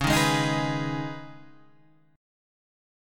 Db7sus2 chord